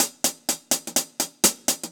Index of /musicradar/ultimate-hihat-samples/125bpm
UHH_AcoustiHatB_125-02.wav